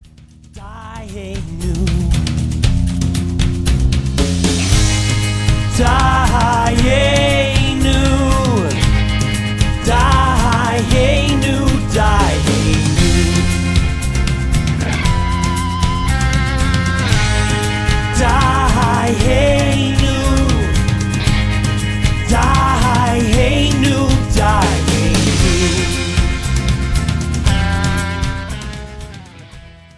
voice-guitar duet